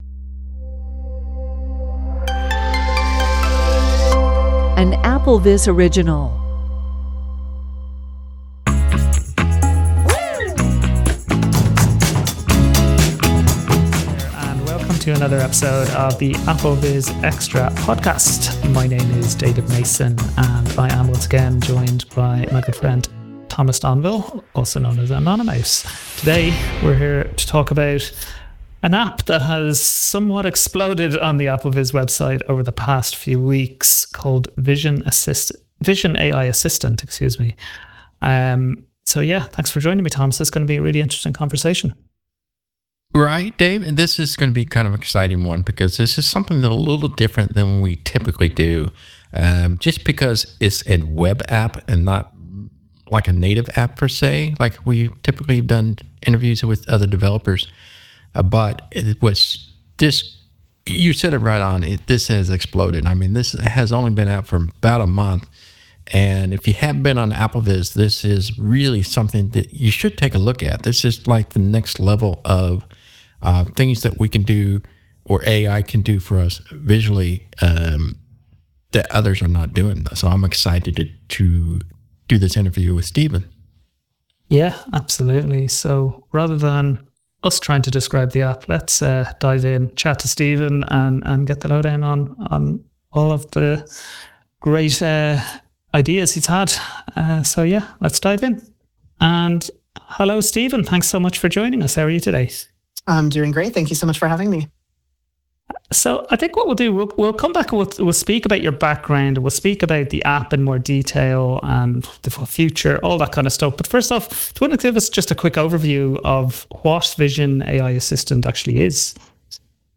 The hosts and guest also discuss privacy considerations, data handling, accessibility trade-offs between web and native apps, and the financial realities of running AI-driven services.